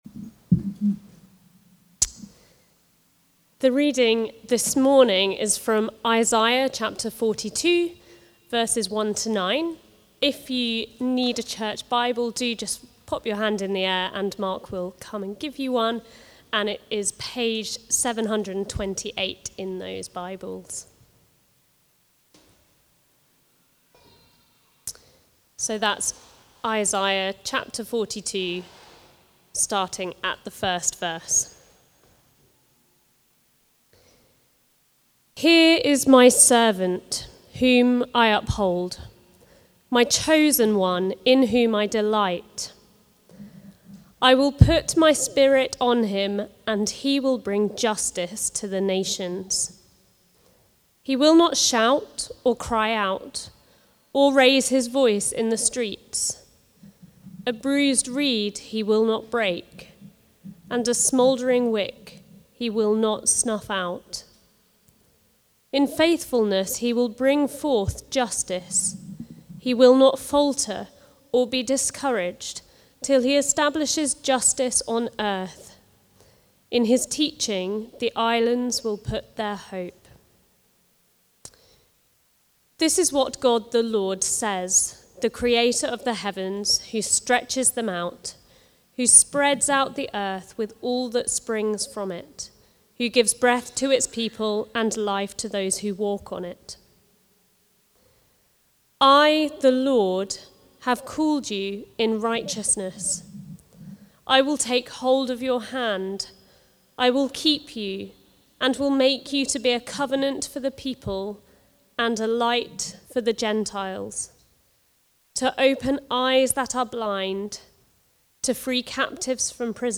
Preaching
Justice Done (Isaiah 42:1-9) from the series Come, Lord Jesus. Recorded at Woodstock Road Baptist Church on 03 August 2025.